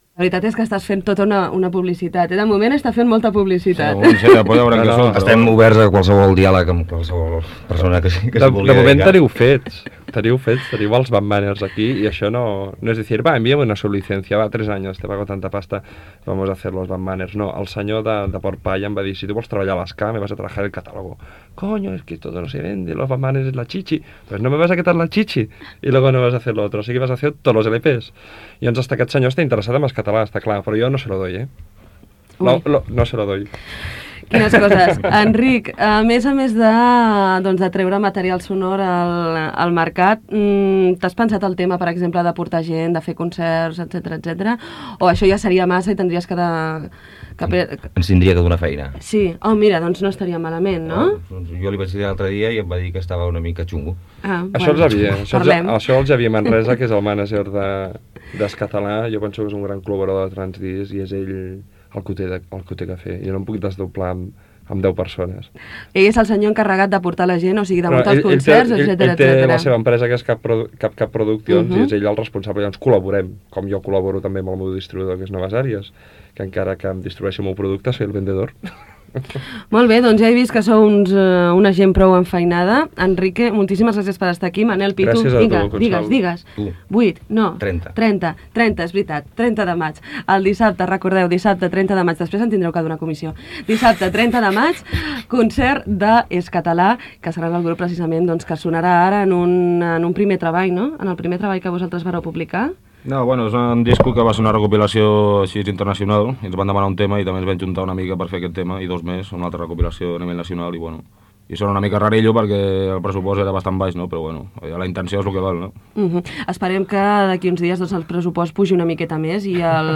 Entrevista a integrants del grup musical Skatalà
Musical